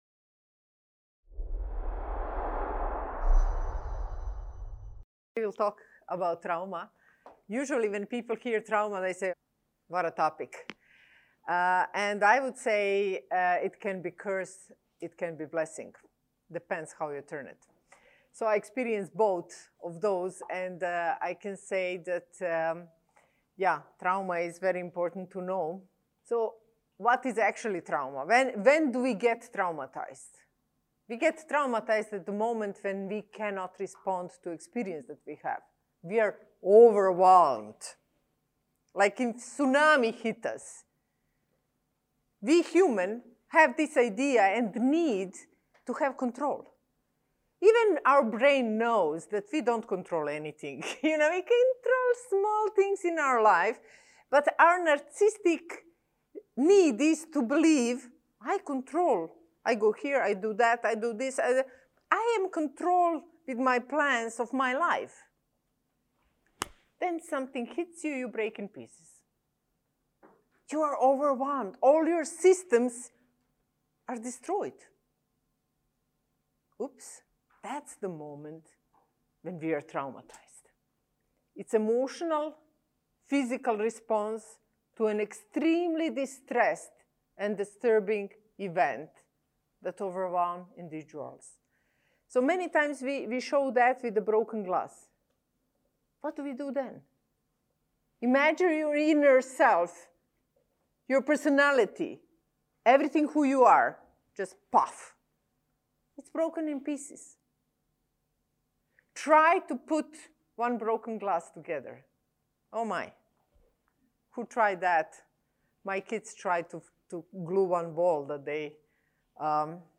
Approaching those who experience trauma requires knowledge and specific skills to which you will be introduced in this talk.